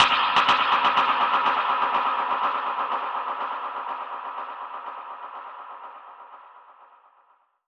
Index of /musicradar/dub-percussion-samples/125bpm
DPFX_PercHit_C_125-04.wav